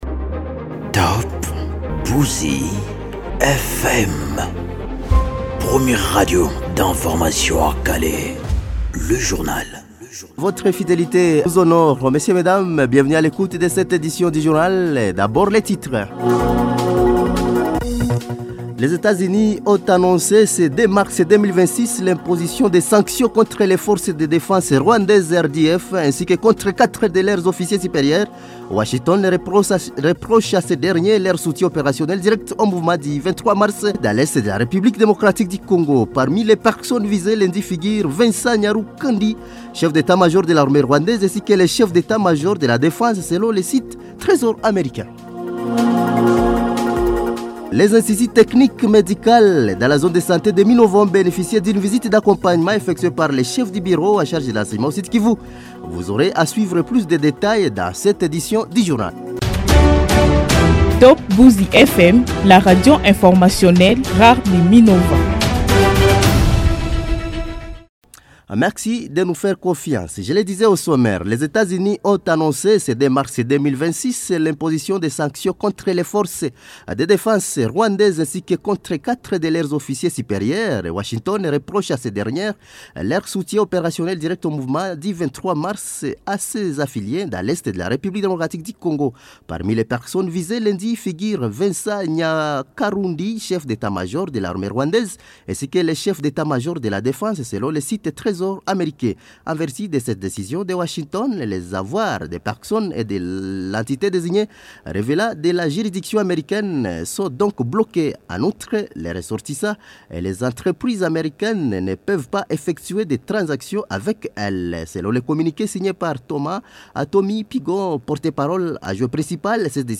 Journal mardi matin 03 mars 2026